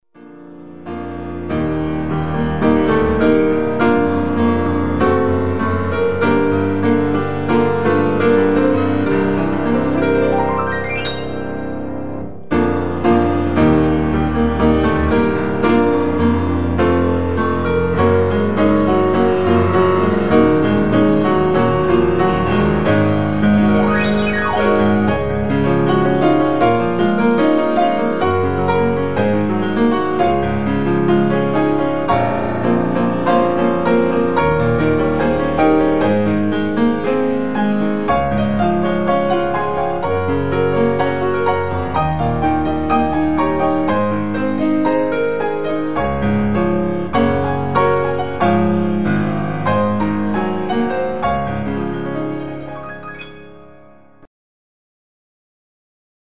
Piano CDs